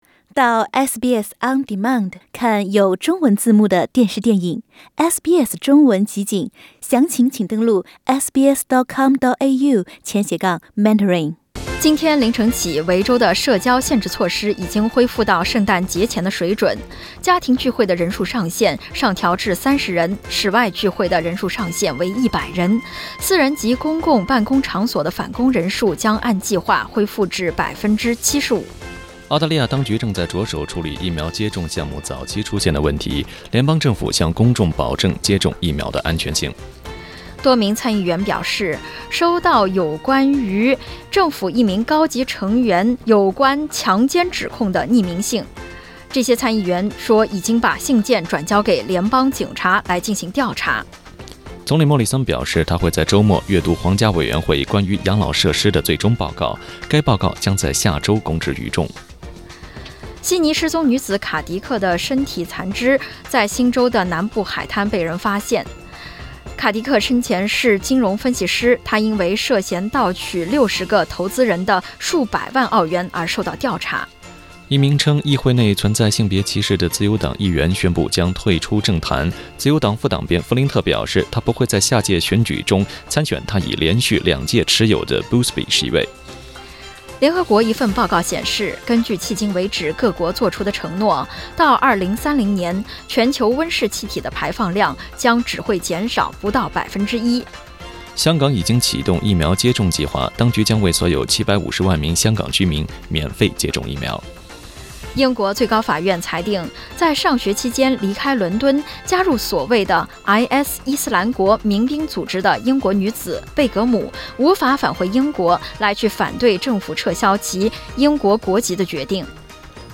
SBS早新聞（2月27日）
SBS Mandarin morning news Source: Getty Images